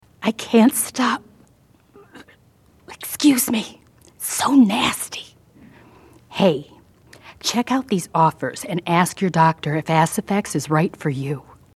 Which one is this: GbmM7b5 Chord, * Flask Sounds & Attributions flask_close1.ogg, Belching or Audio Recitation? Belching